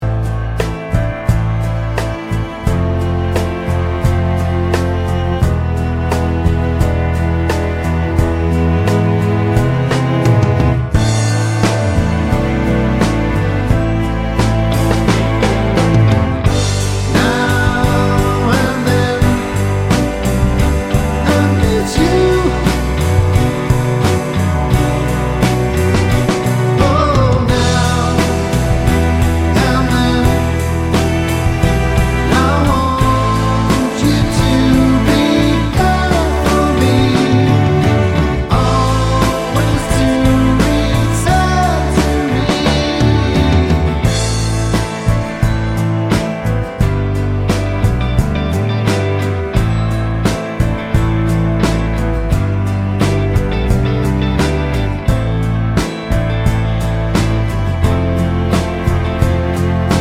No Harmony Pop (1960s) 4:04 Buy £1.50